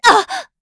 Isolet-Vox_Damage_kr_04.wav